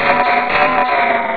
Cri de Vacilys dans Pokémon Rubis et Saphir.